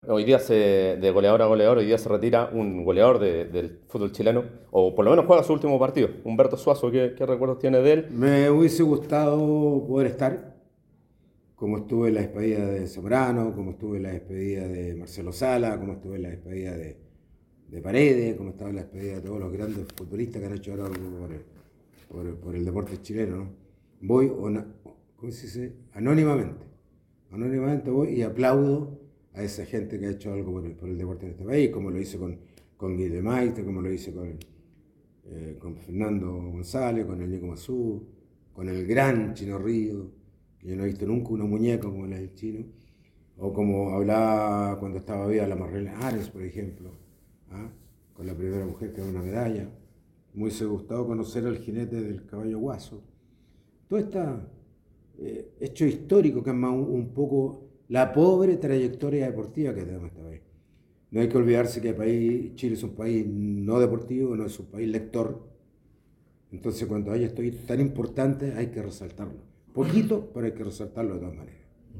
Carlos Caszely en diálogo con ADN Deportes